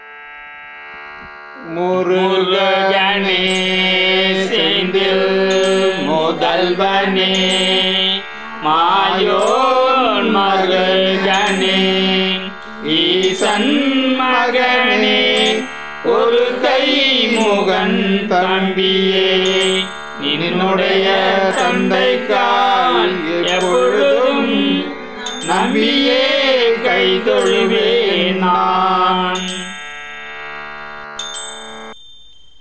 பாடியவர்கள்:    சங்கீர்த்தன இயக்கம் பக்த்தர்கள்